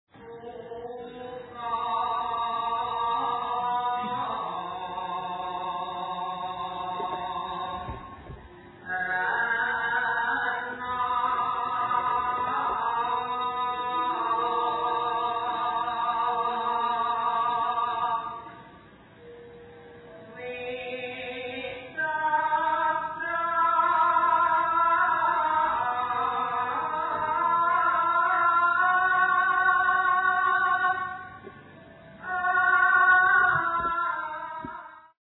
in the field in 1974